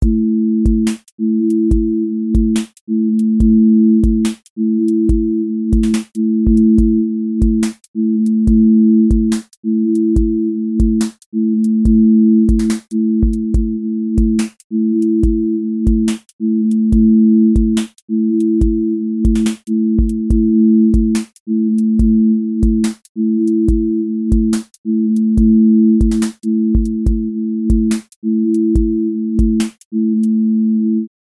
drill glide tension with sliding low end
• macro_drill_core
Drill glide tension with sliding low end